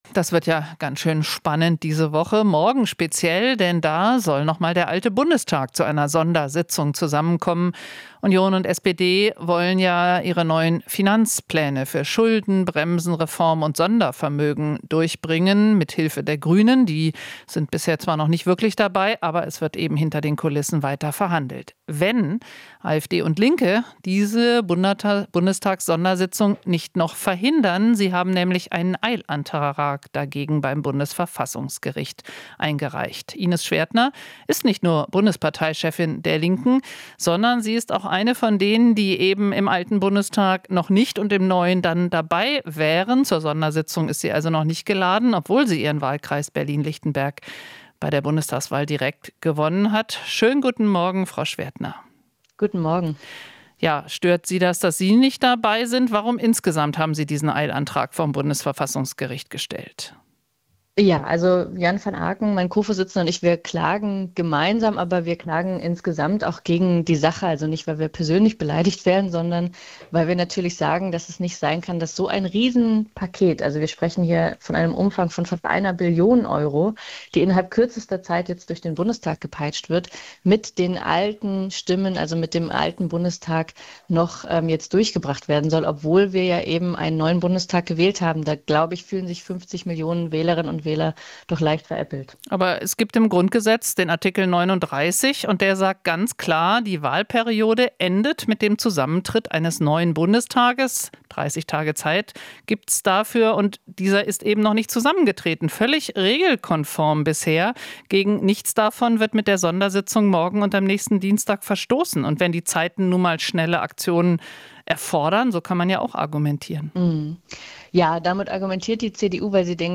Interview - Schwerdtner (Linke) kritisiert Einberufung des alten Bundestages